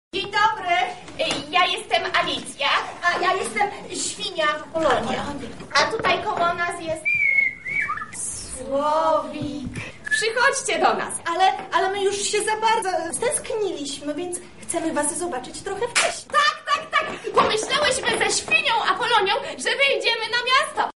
-zapraszają bohaterowie „Z głową w chmurach”.